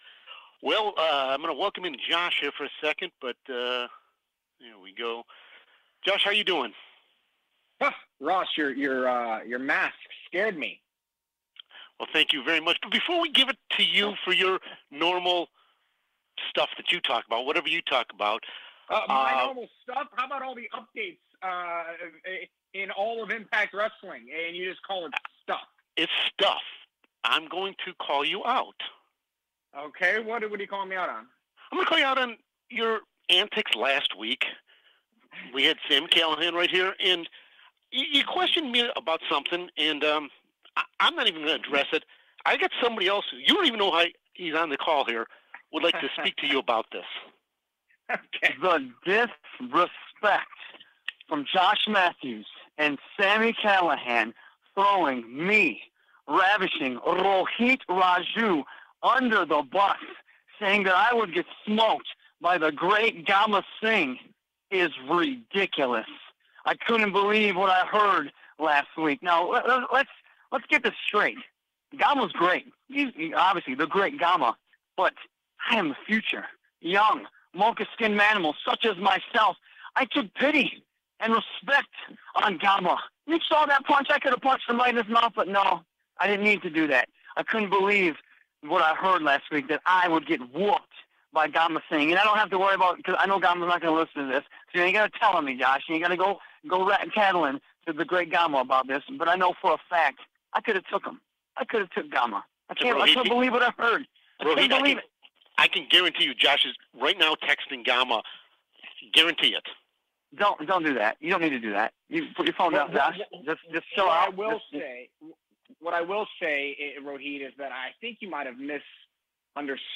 INTERACTIVE WRESTLING RADIO INTERVIEW - TAYA VALKYRIE Show
Taya Valkyrie joined the GFW teleconference this week....